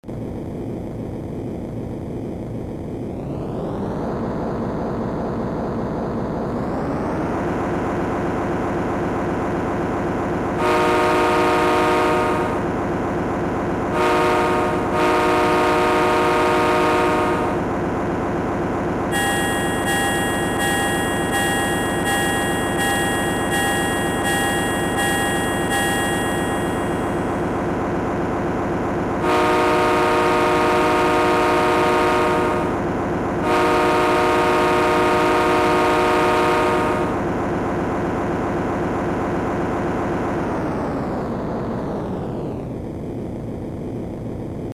00036_Sound_Locodiesel.mp3